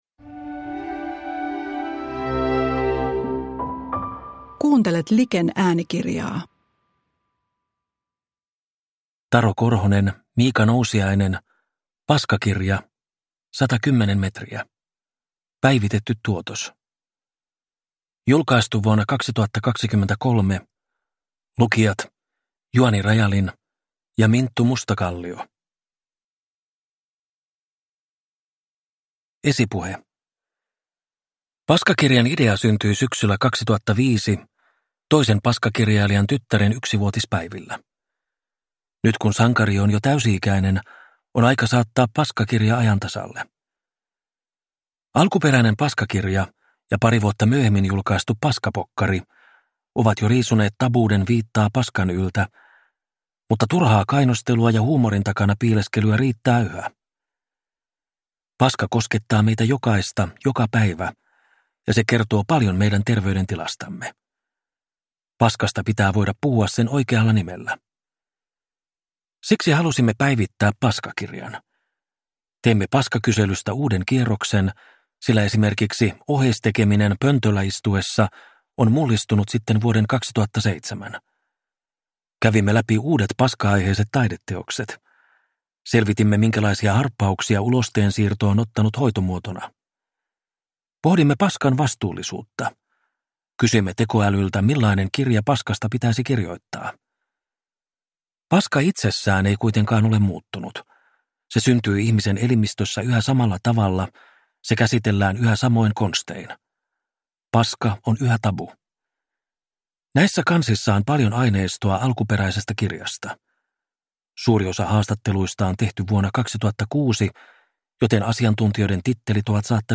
Paskakirja – Ljudbok